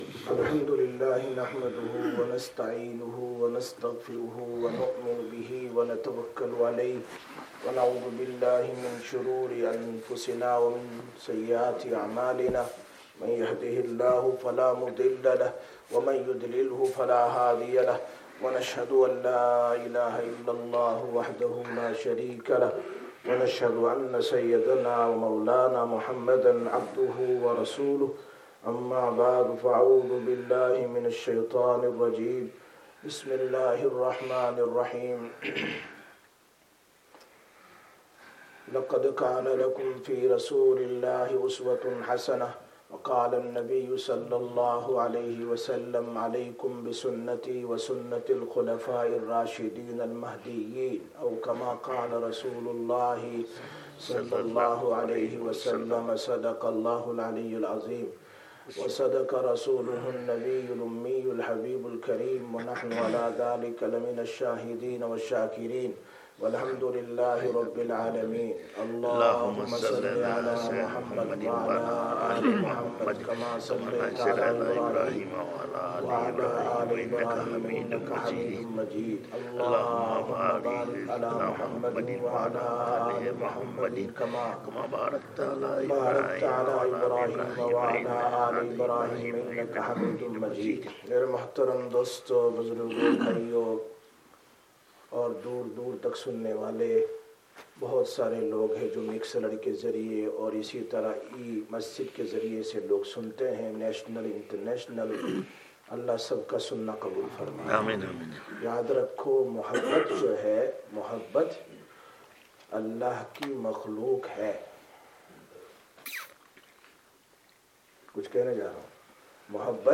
27/09/2024 Jumma Bayan, Masjid Quba